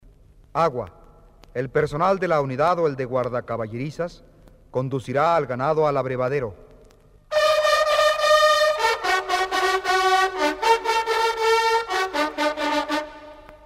TOQUES MILITARES CON TROMPETA PARA EL ARMA DE CABALLERIA
agua.mp3